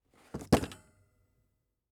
PlaceBoxDown-SFX Producer.wav